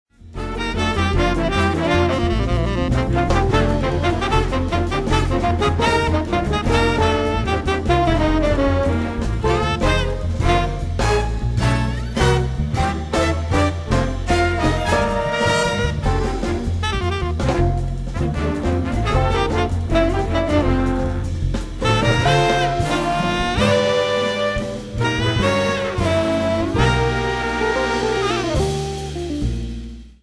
LIVE AT THE BARBICAN, LONDON 07/03/2004
SOUNDBOARD RECORDING